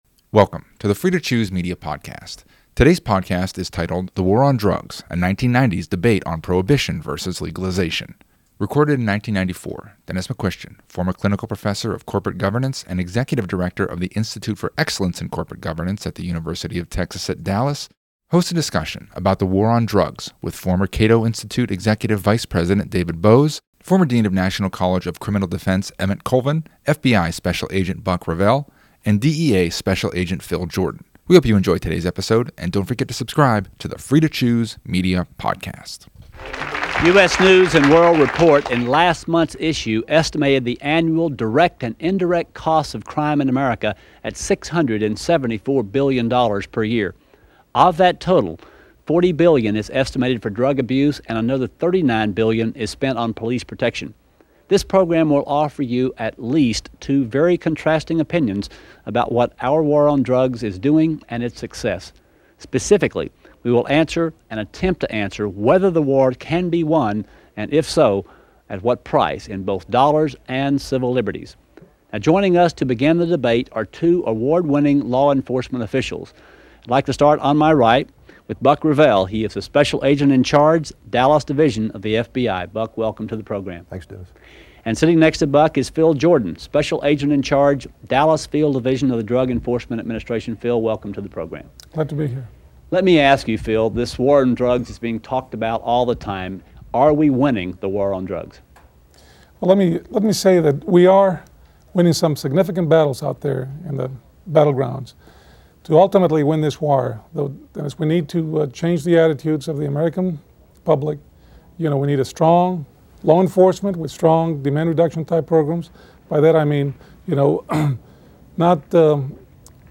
Episode 252 – The War on Drugs: A 1990s Debate on Prohibition versus Legalization